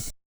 hihat.wav